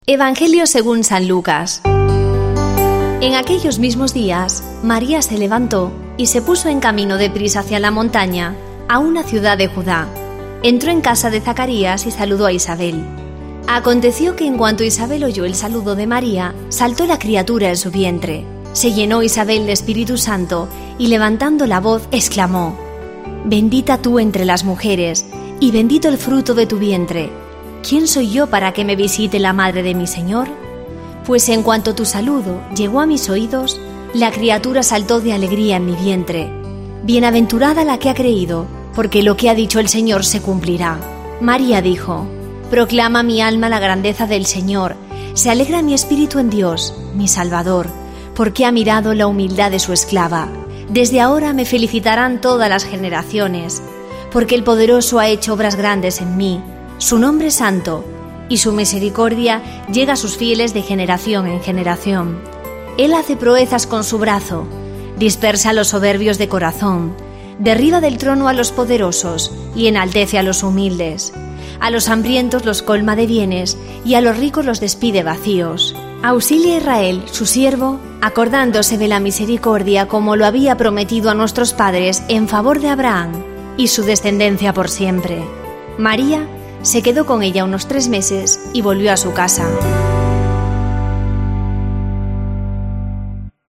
Lectura del santo Evangelio según san Lucas 1, 39-56En aquellos días, Maria se puso en camino y fue aprisa a la montaña, a un pueblo de Judá; entró en...